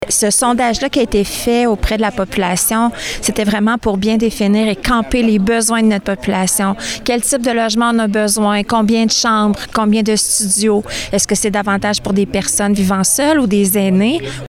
La mairesse de Bécancour, Lucie Allard, a estimé que ce sondage permettait de mesurer les besoins à long terme.